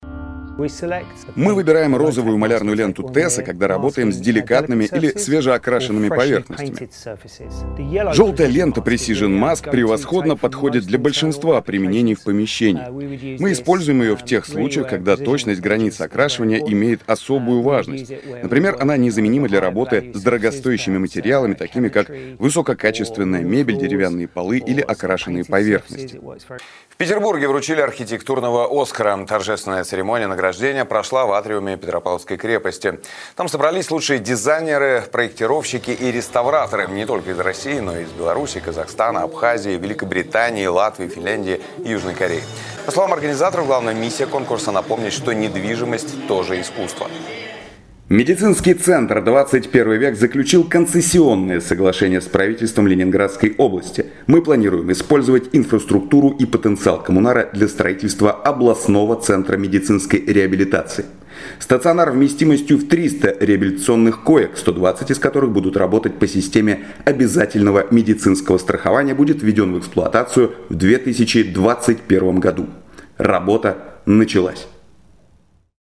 Приятный, легко узнаваемый тембр голоса. Баритон.